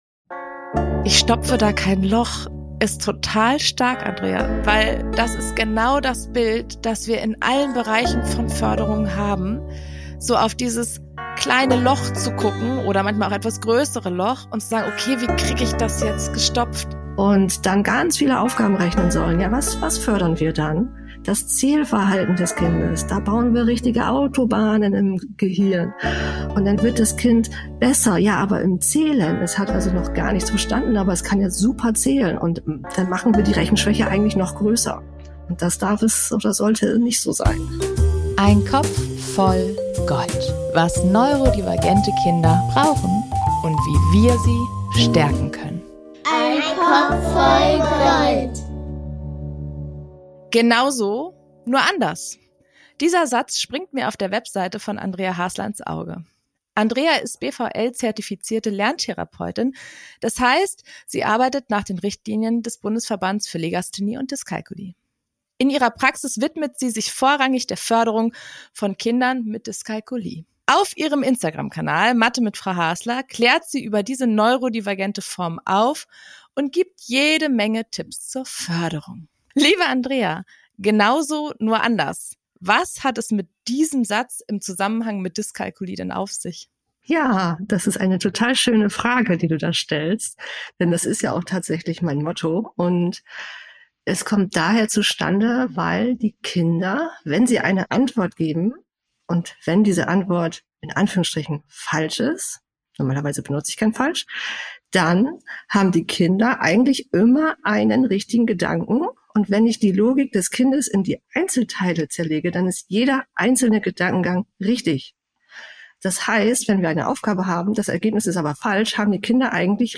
Warum Kinder mit Rechenschwäche nicht schlecht rechnen – Ein Interview